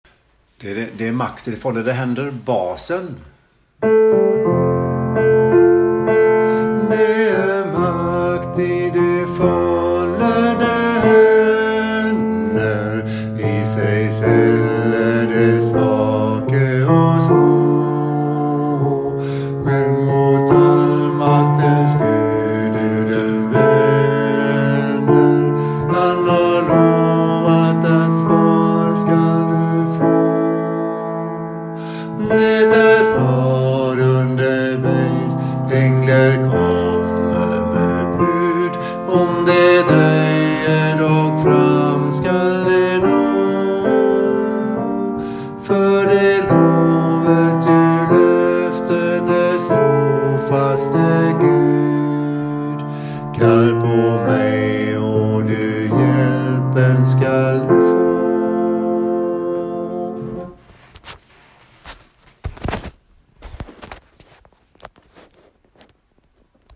Det är makt i de follede hender bas
det er makt_bas.mp3